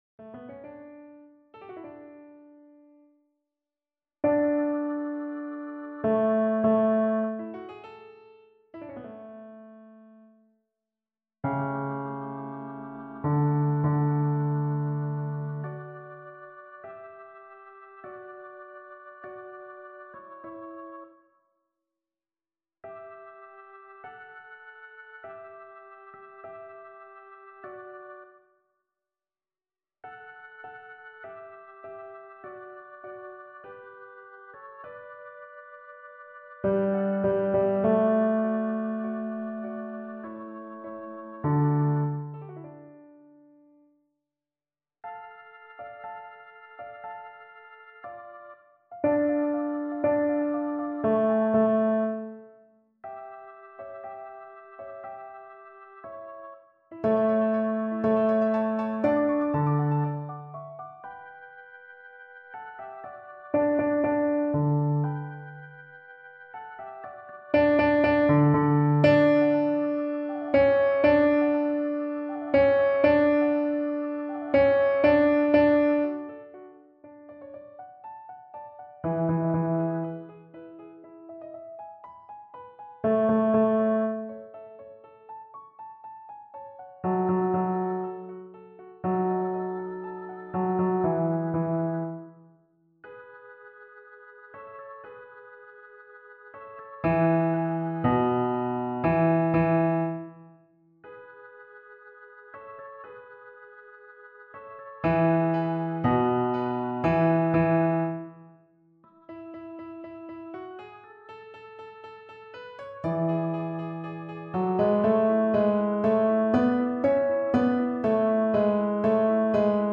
Fichiers de Travail des Basses